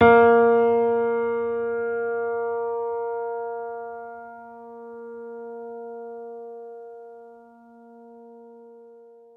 Vintage_Upright